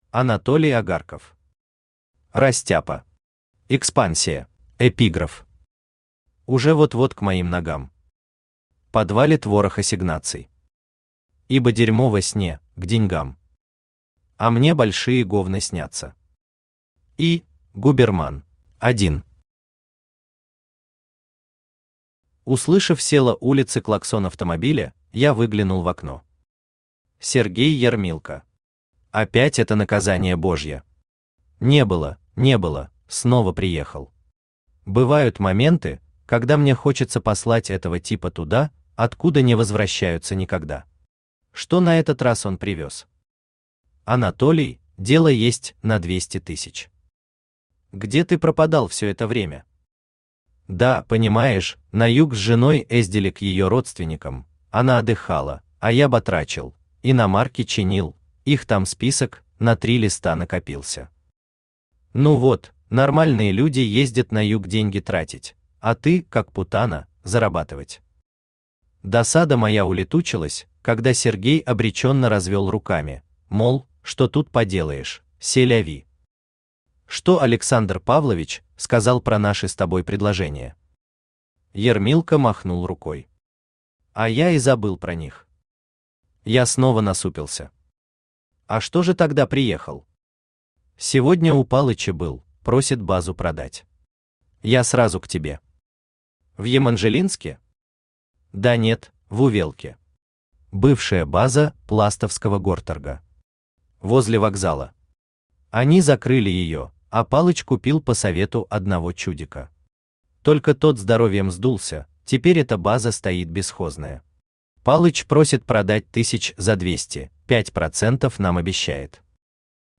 Аудиокнига Растяпа. Экспансия | Библиотека аудиокниг
Экспансия Автор Анатолий Агарков Читает аудиокнигу Авточтец ЛитРес.